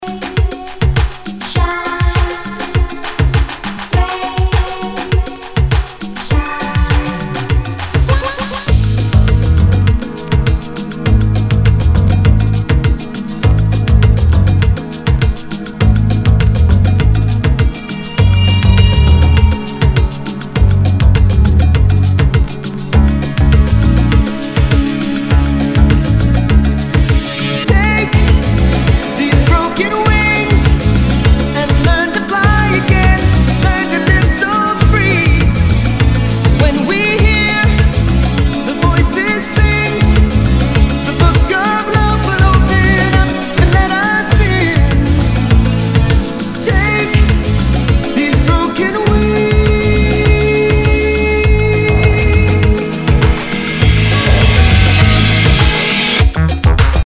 SOUL/FUNK/DISCO
ナイス！カットアップ / シンセ・ポップ・ディスコ！
Dj Mix